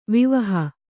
♪ vivaha